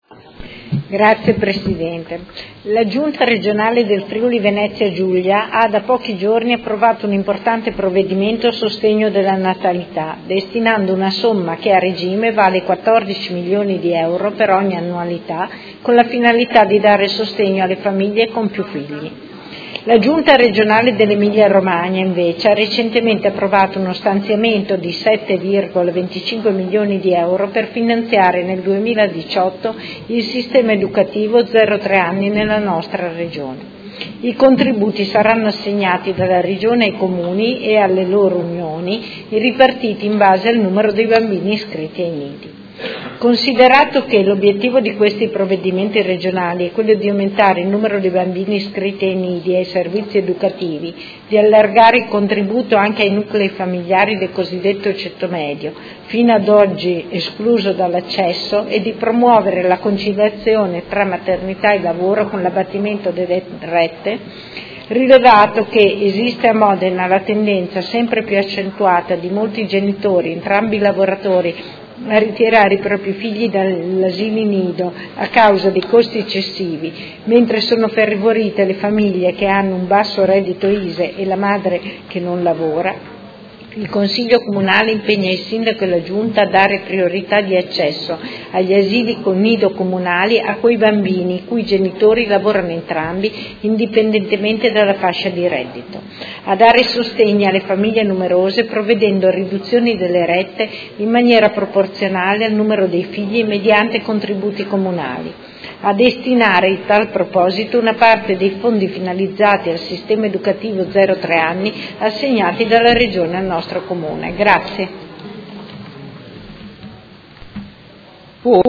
Luigia Santoro — Sito Audio Consiglio Comunale
Seduta del 20/12/2018. Presenta Ordine del Giorno Prot. Gen. 205014